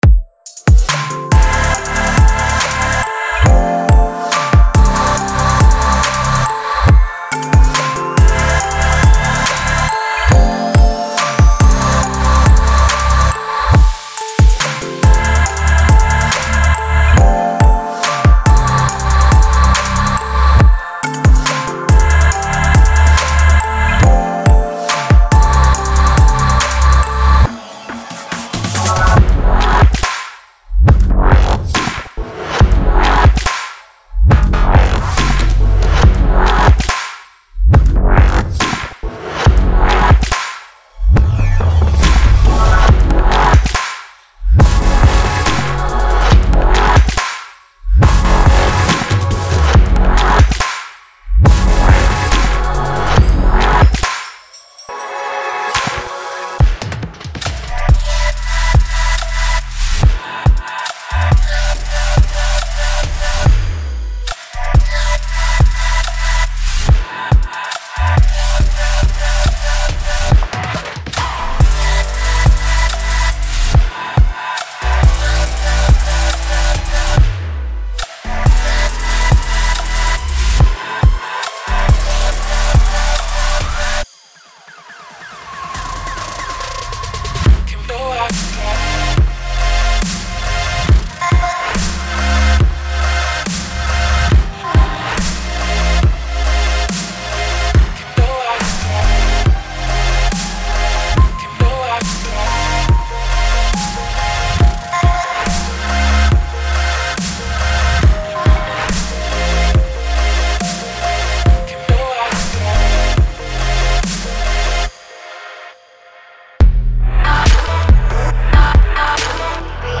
EDMHouseTrap